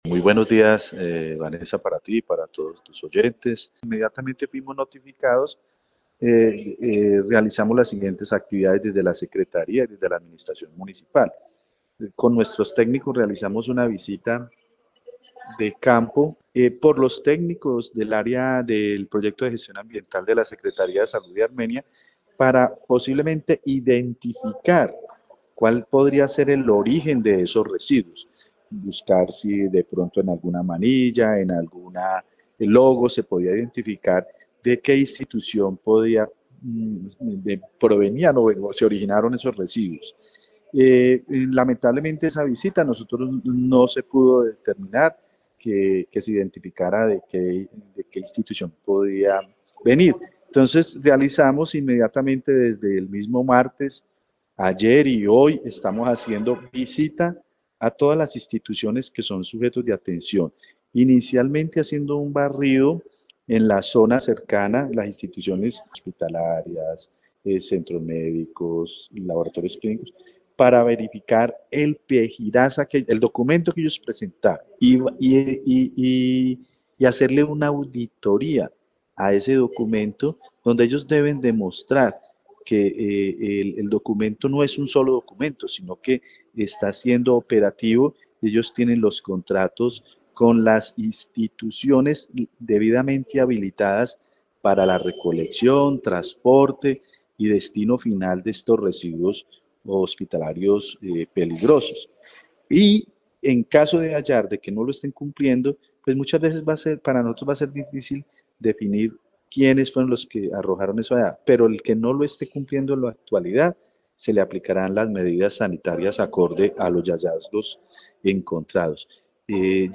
Secretario de Salud de Armenia
En diálogo con Caracol Radio, el secretario de Salud de Armenia, César Augusto Rincón indicó que fueron notificados sobre la situación y de inmediato desde sus competencias iniciaron con las respectivas investigaciones con el objetivo de determinar los responsables de este hecho.